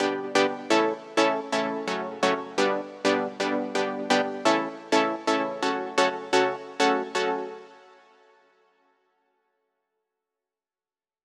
VDE1 128BPM Full Effect Chords Root G SC.wav